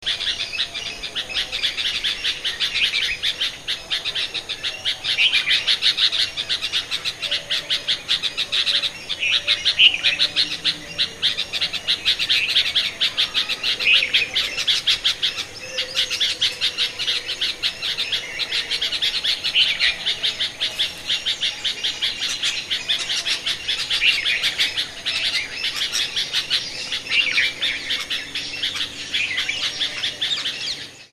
Scolding and alarm calls, to announce danger in the vicinity, a typical alarm call often being a rapidly uttered single note announcing the approach of danger with high risk, e.g. Red-vented Bulbul.
Red-vented Bulbul – scalding calls
2.Red-vented-Bulbul-alarm-mobbing-calls.mp3